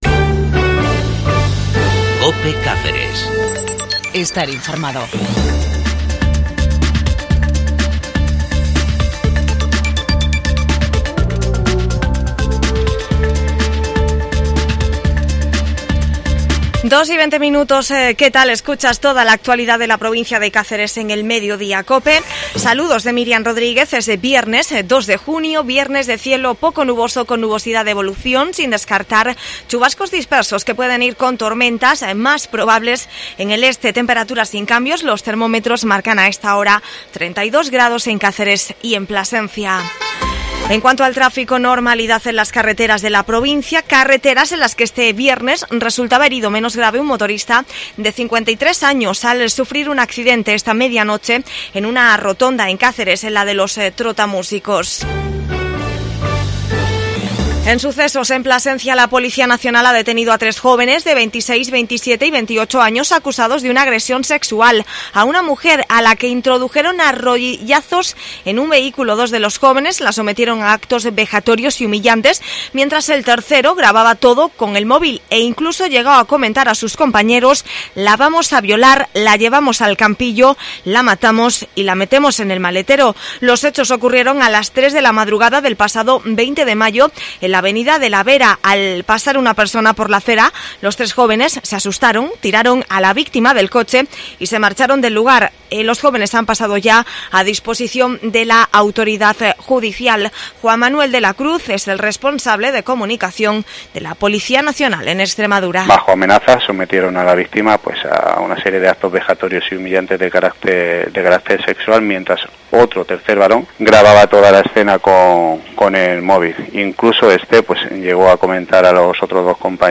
AUDIO: INOFRMACION LOCAL CACERES